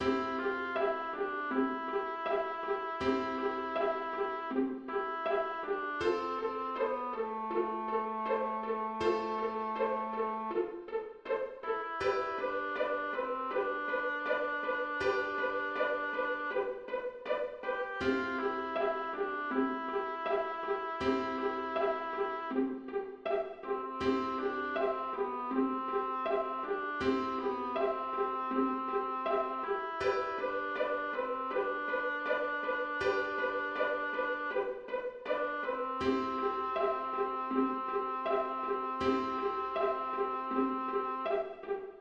Schlaflied
Oboe
(Hier die Melodie des Liedes)